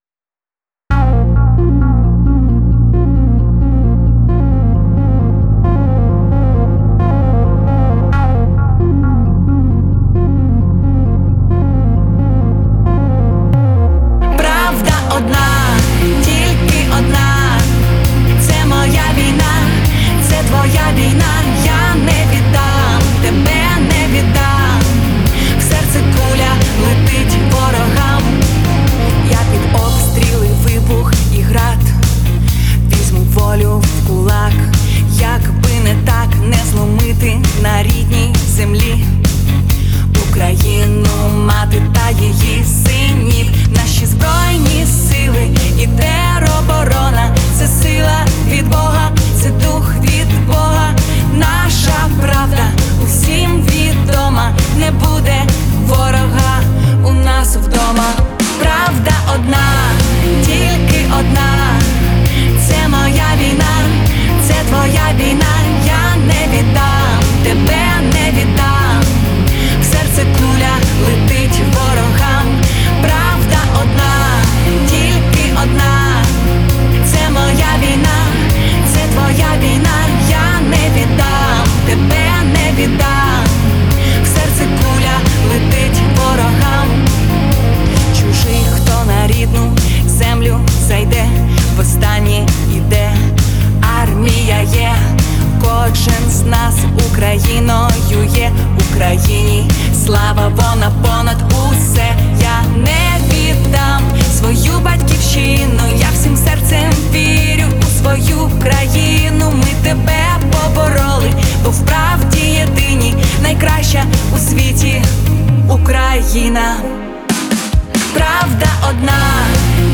Звучание песни отличается мелодичностью и искренностью